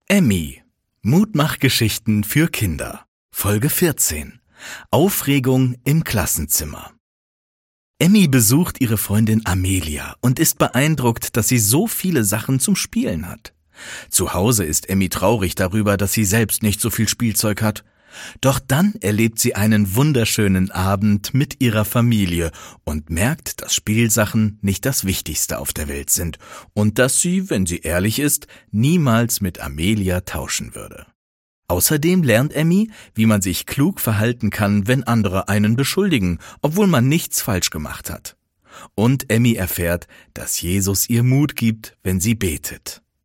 (MP3-Hörspiel - Download)
Hörspiele